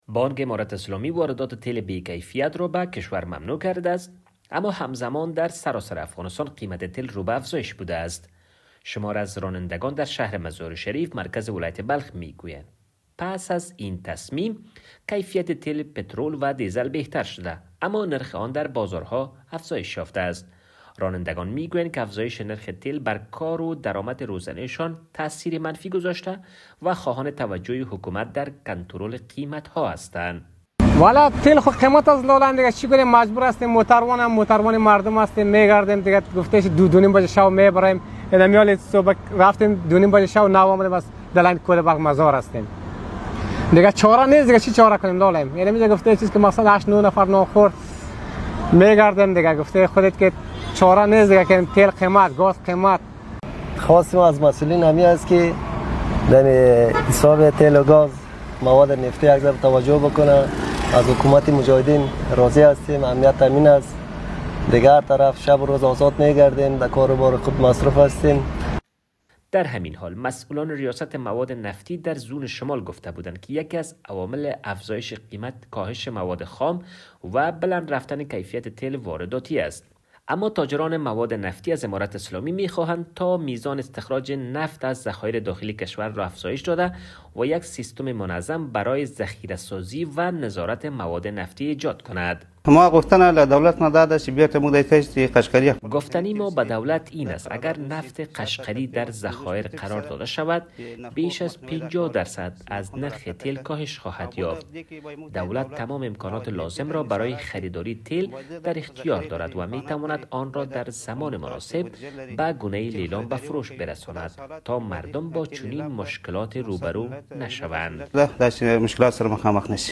شماری از رانندگان در شهر مزارشریف، مرکز ولایت بلخ، می‌گویند که کیفیت تیل پترول و دیزل بهتر شده، اما نرخ آن در بازارها افزایش یافته است.